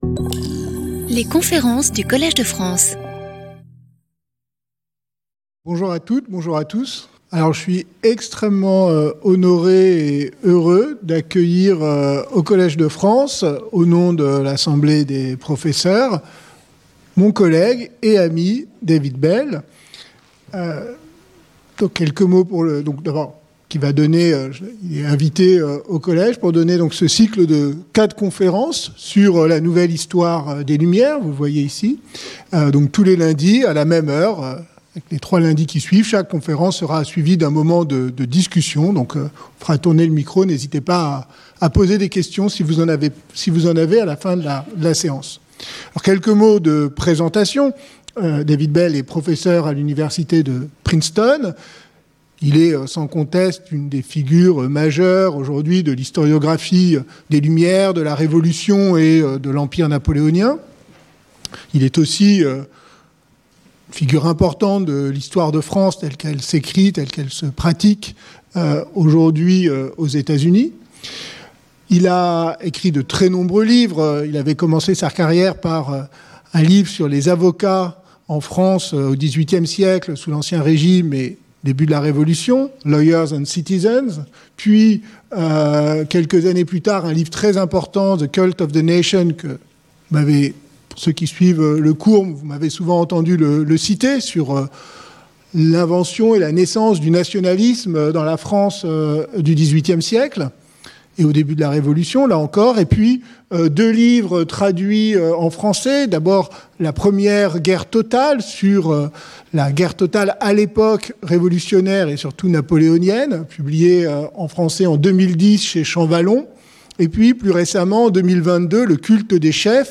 Conférencier invité